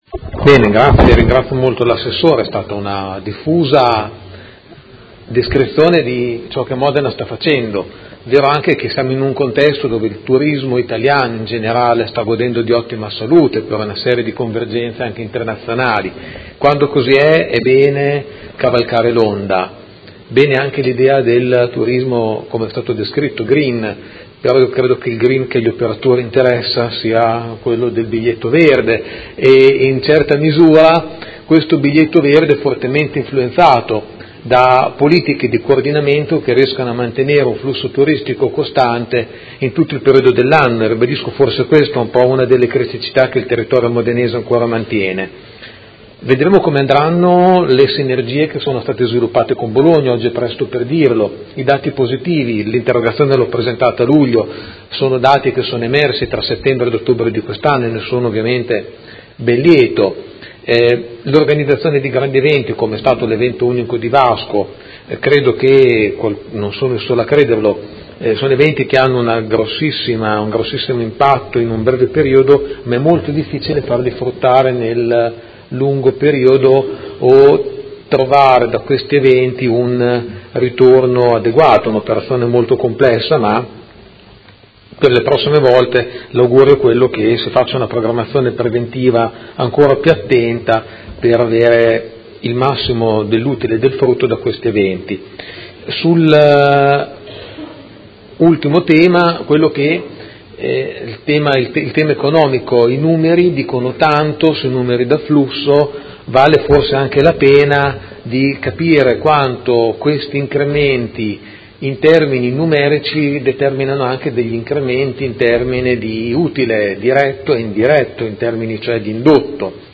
Seduta del 23/11/2017 Replica a risposta Assessora Ferrari. Interrogazione del Consigliere Pellacani (FI) avente per oggetto: Dopo Vasco, quale piano per il turismo?